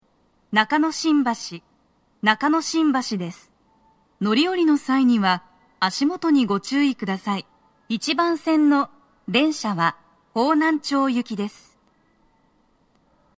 スピーカー種類 TOA天井型()
足元注意喚起放送が付帯されています。
1番線 方南町方面 到着放送 【女声